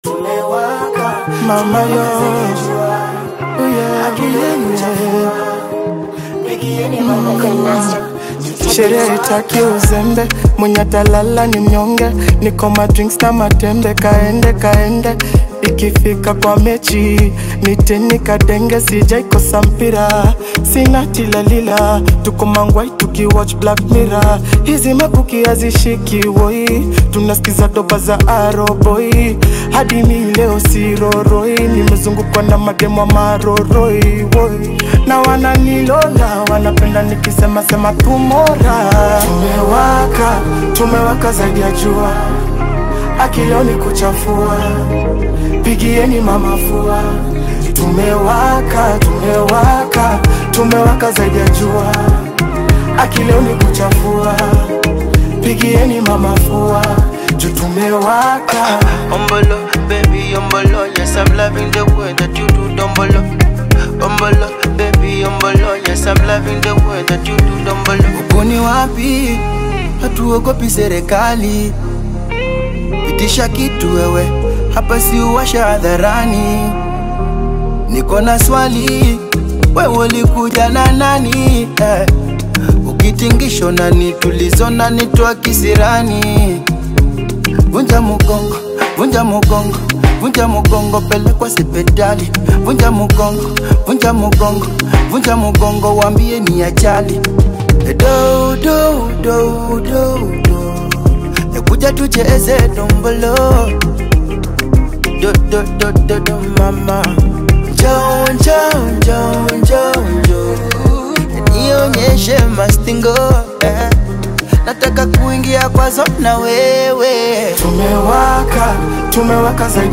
emotionally charged